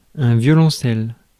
Prononciation
Synonymes violoncelliste Prononciation France: IPA: /vjɔ.lɔ̃.sɛl/ Abréviations et contractions (musique) Vlc.